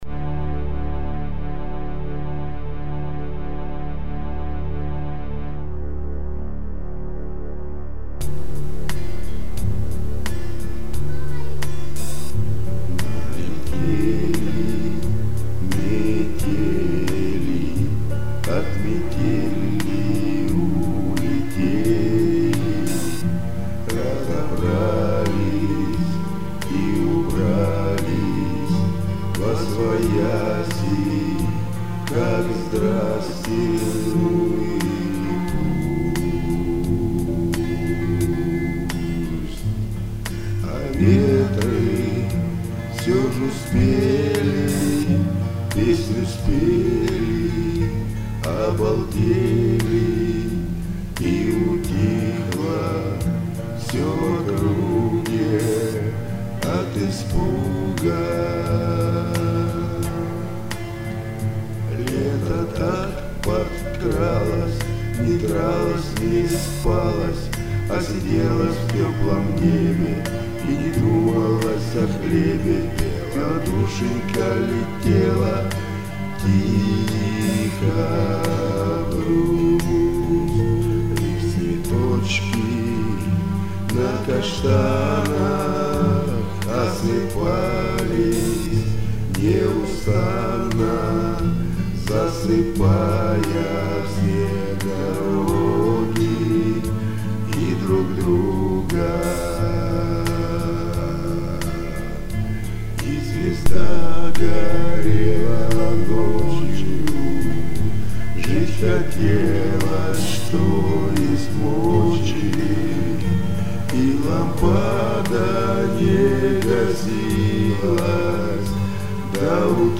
• Жанр: Блюз